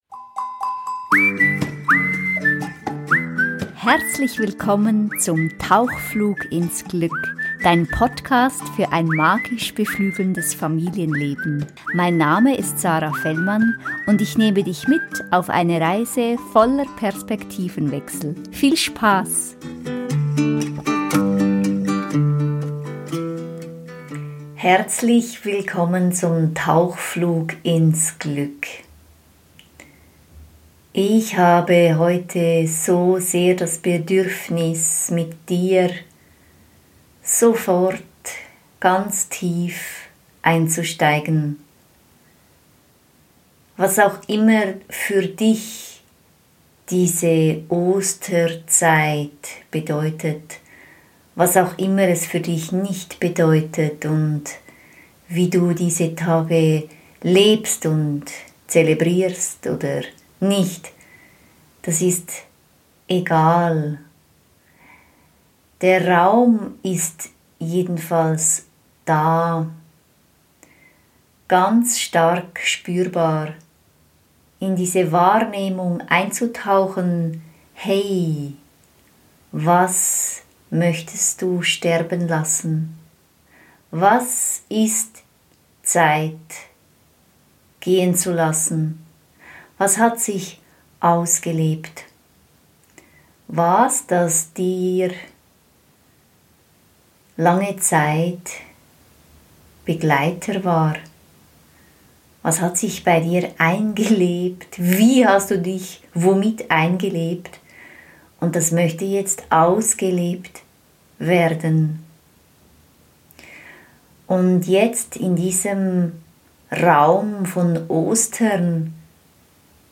Dieser Tauchflug ist ein Gebet, eine tiefe Trancereise.